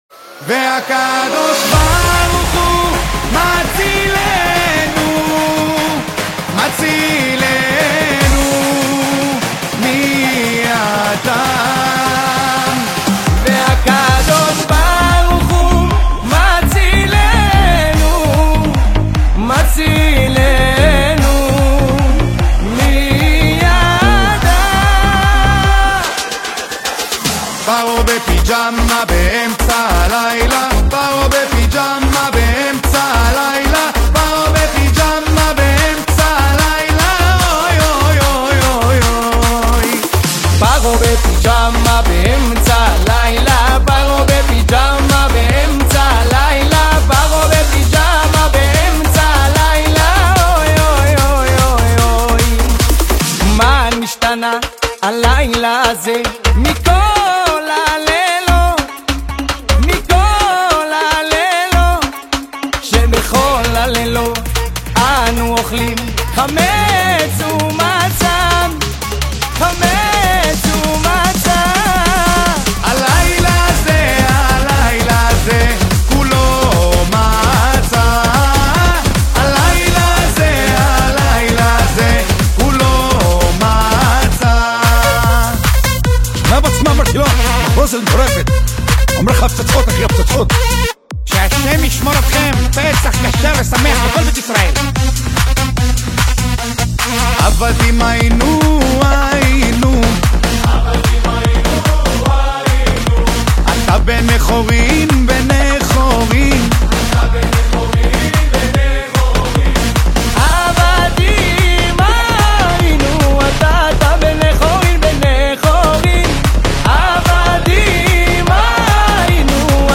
שמח לשתף קאבר חדש שהוצאתי לקראת חג הפסח